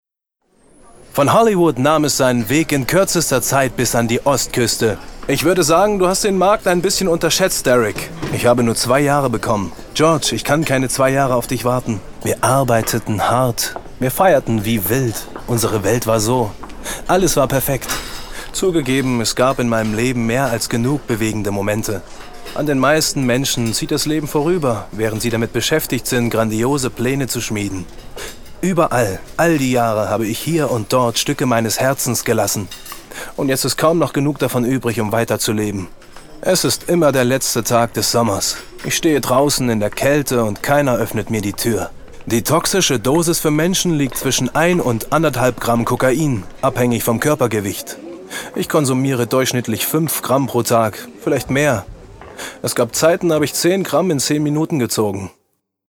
ein Junger dynamischer und frischer Sprecher - gesanglich und spielerisch ausgebildet.
Kein Dialekt
Sprechprobe: Sonstiges (Muttersprache):